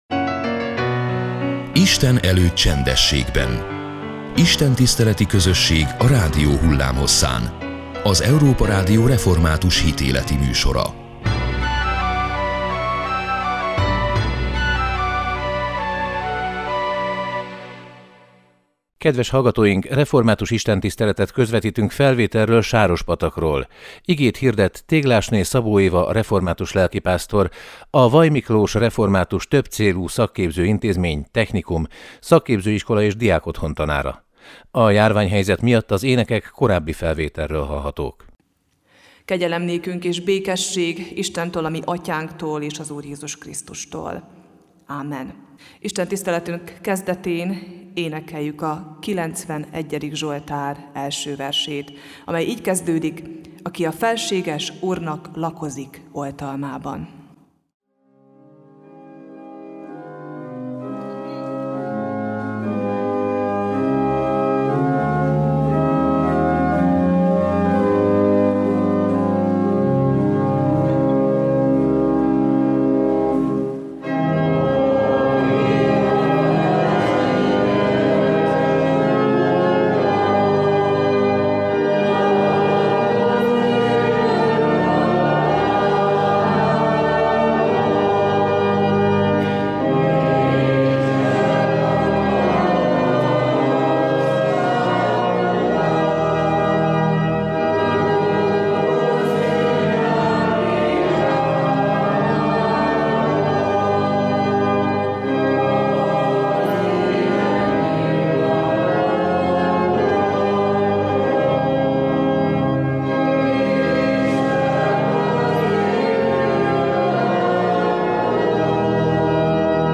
Református istentiszteletet közvetítettünk felvételről Sárospatakról.